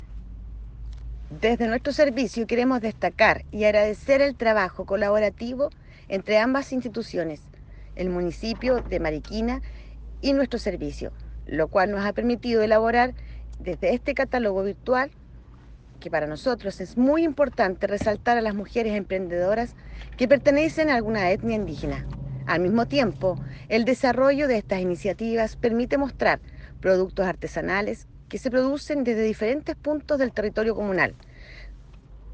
CUÑA-02-DIRECTORA-REGIONAL-SERNAMEG-.mp3